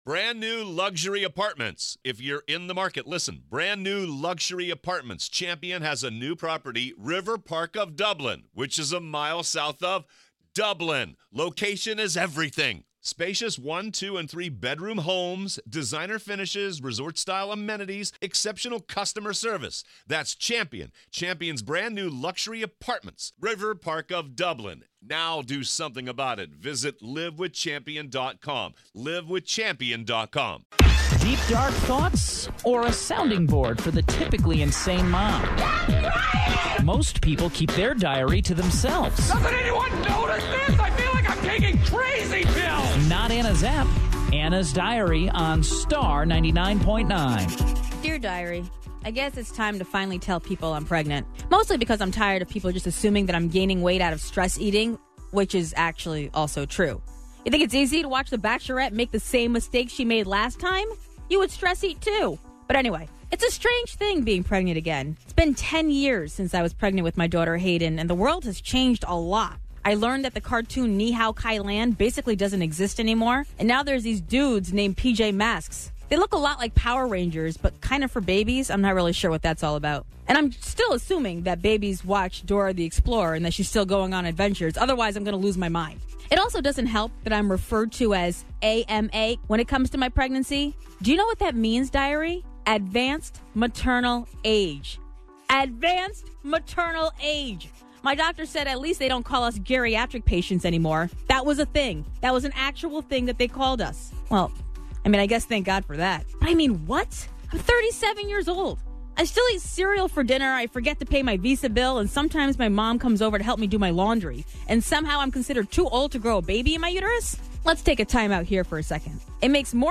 because she reads her diary entries on the air! This time, it's all about telling everyone that she's indeed pregnant, and some of the misconceptions about it!